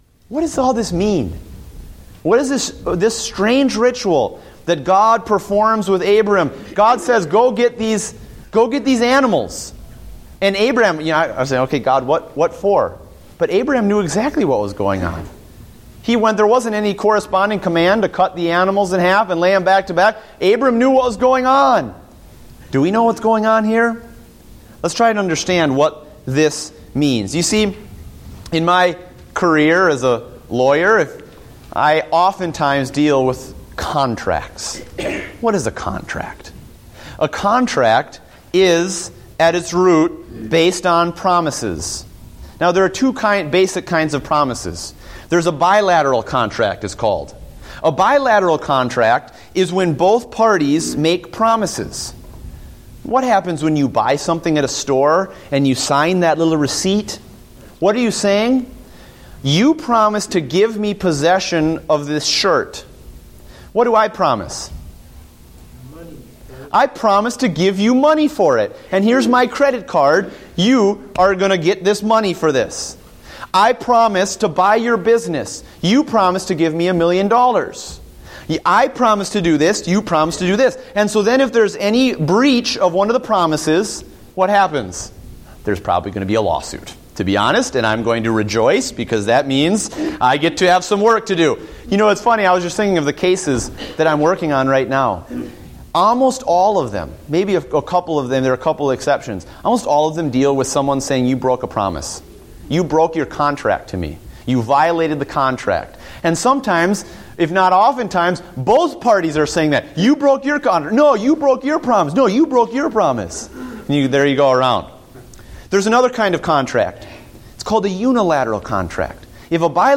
Date: September 21, 2014 (Adult Sunday School)